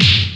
fist.wav